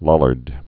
(lŏlərd)